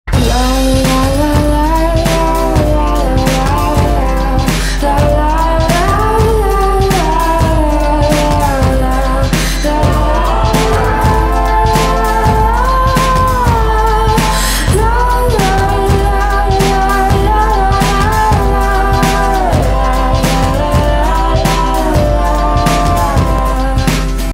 Or use the built-in vocoder to unleash classic synth-robot sounds.
The focused 256-band articulate vocoder, designed by the same EMS genius who made vocoding famous, features adjustable harmonic enhancement and controllable formant shift.
Voice-BoxVocal-Harmony-Machine-Vocoder-Mode.mp3